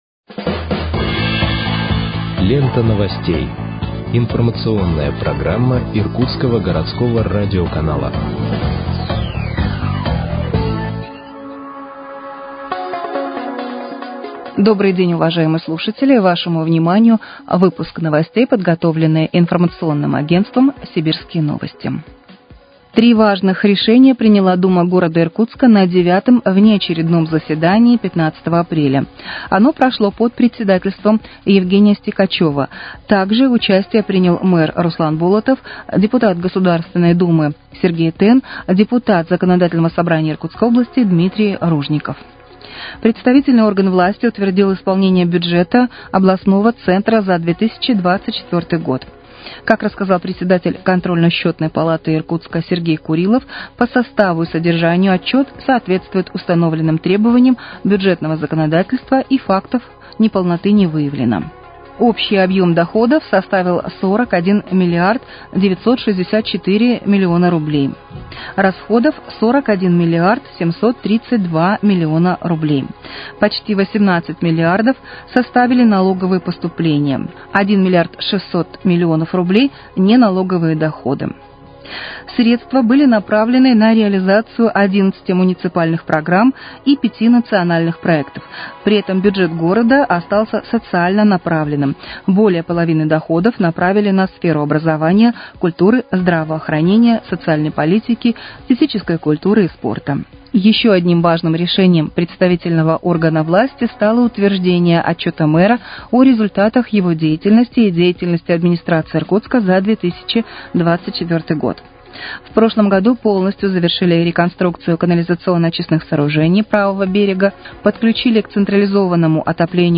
Выпуск новостей в подкастах газеты «Иркутск» от 17.04.2025 № 2